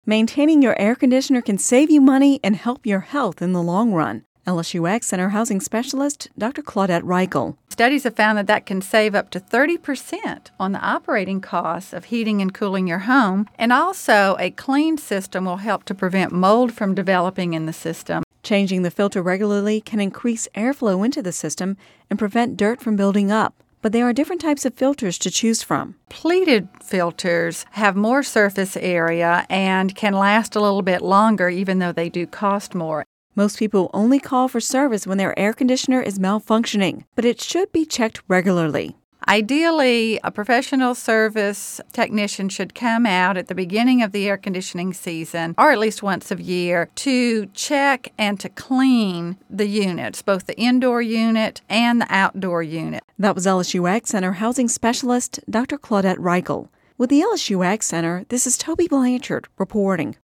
(Radio News 05/17/10) Maintaining your air conditioner can save you money and help your health in the long run